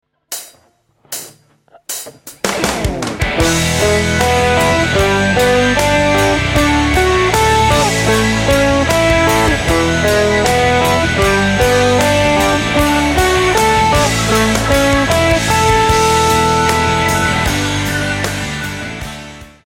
In this guitar lesson the CAGED Chord System will be used over a 4 chord progression showing ideas to create a guitar solo based on arpeggios.
The chords in the example are E G D and A major which are the same chords used in the chorus of Alive by Pearl Jam and countless other songs.
The first exercise uses the E major shape shifting it up and down the neck for each chord.
CAGED E Chord Shape Arpeggios